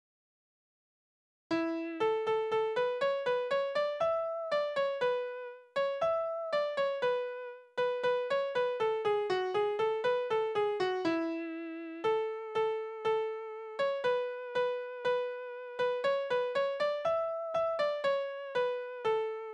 Balladen: Es blies ein Jäger wohl in sein Horn
Tonart: A-Dur
Taktart: C (4/4)
Tonumfang: große None
Besetzung: vokal